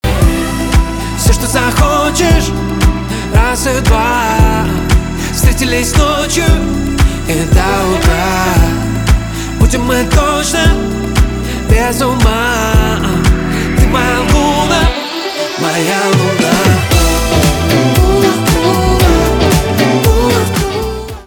поп
нарастающие , скрипка , хлопки , романтические